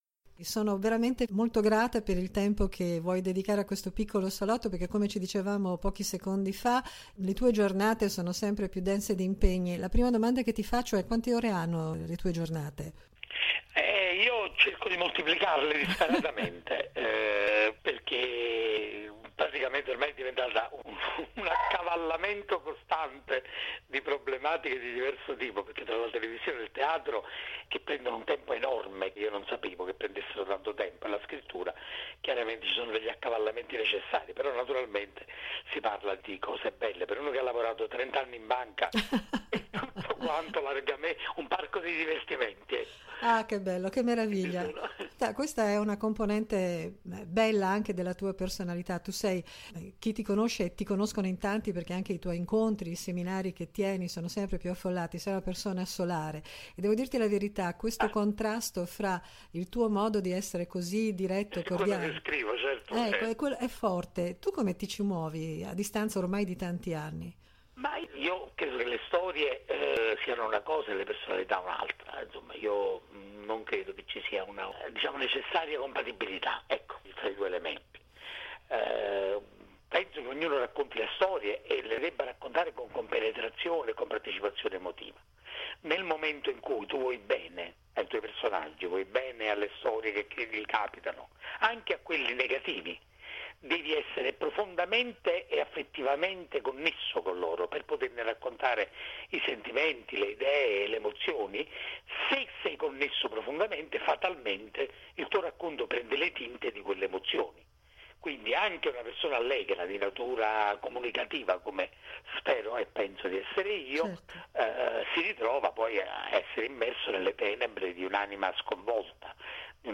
“Cuccioli per i Bastardi di Pizzofalcone”: chiacchierata con Maurizio de Giovanni
Ecco di seguito l’intervista a Maurizio de Giovanni, il cui sonoro trovate in alto, nella sezione audio di questa pagina.